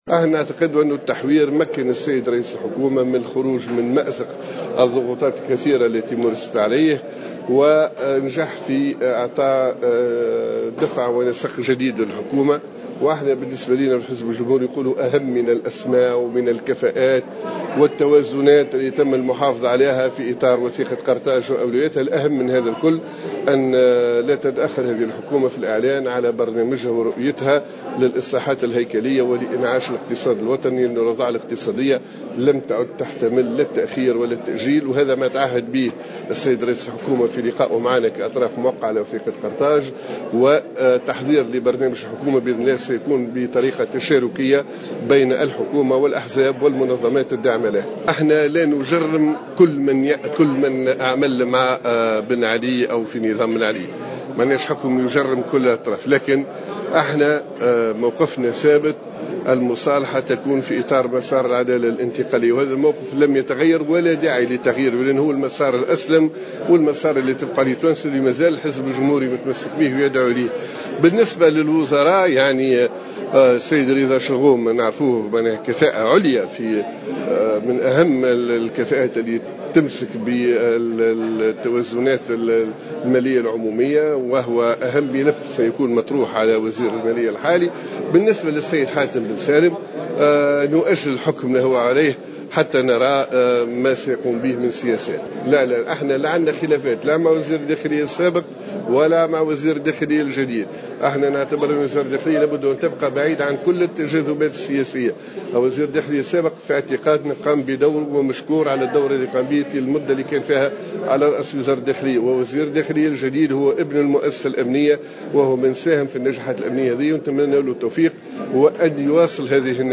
وأضاف في تصريح لمراسل الجوهرة اف ام اليوم السبت 09 سبتمبر 2017، أن رئيس الحكومة تعهد خلال لقاء جمعه بالأطراف الموقعة على وثيقة قرطاج بالإعلان عن برنامج وخطة عمل الفريق الحكومي الجديد للخروج بالبلاد من أزمتها وخاصة اقتصاديا.